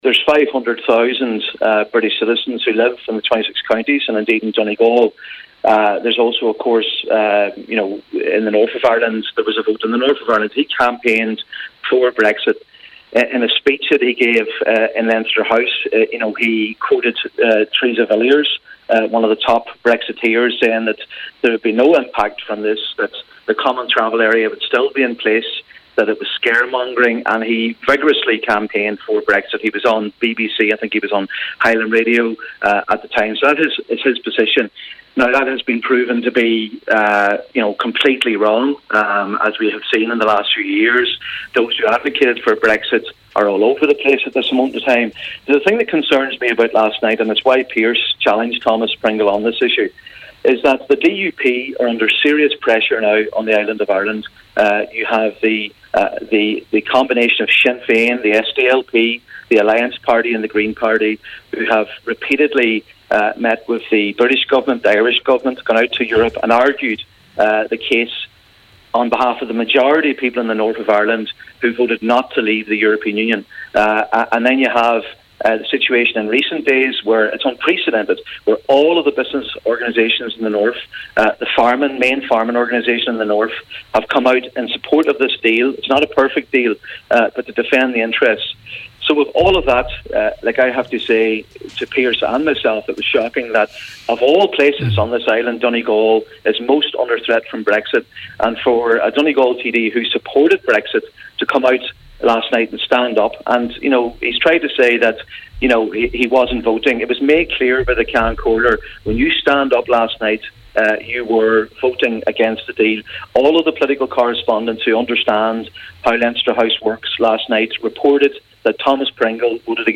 On the Nine til Noon show this morning, Deputy Pringle rejects this, saying he had supported a Sinn Fein amendment just minutes before. He and Senator Padraig Mac Lochlainn discussed the issue on the Nine til Noon Show this morning………….. http